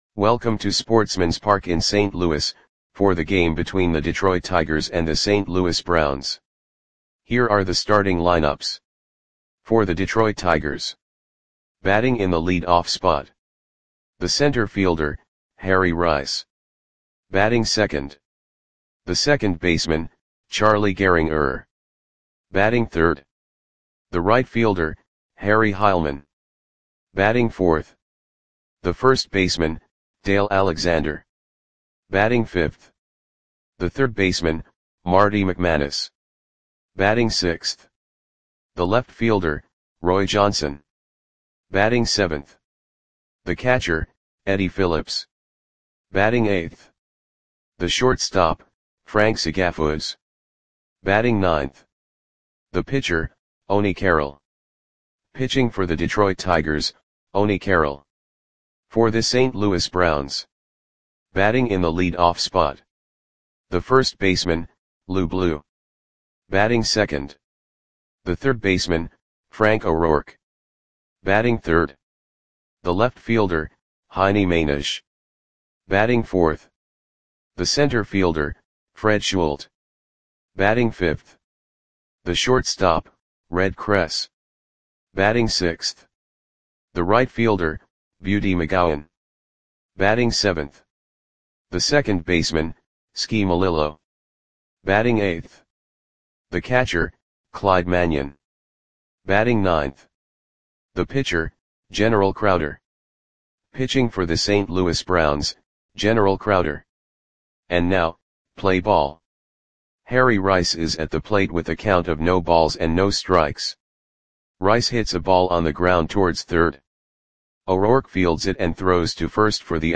Audio Play-by-Play for St. Louis Browns on April 23, 1929
Click the button below to listen to the audio play-by-play.